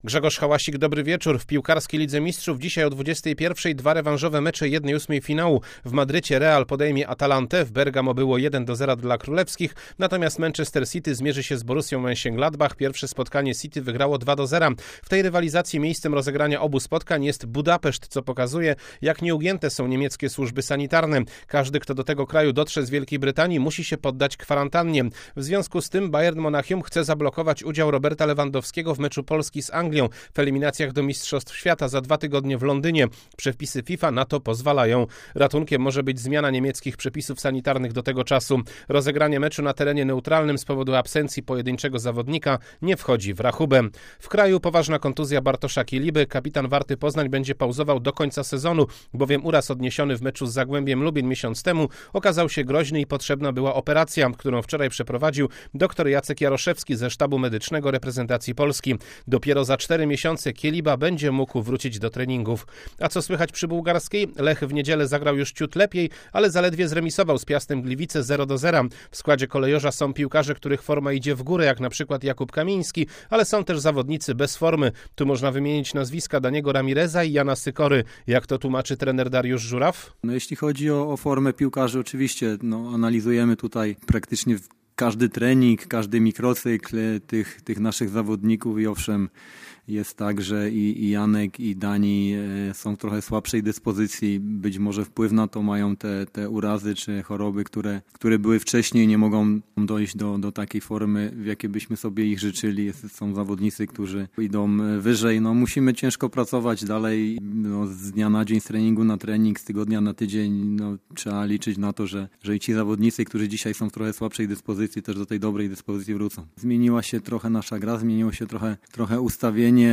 16.03.2021 SERWIS SPORTOWY GODZ. 19:05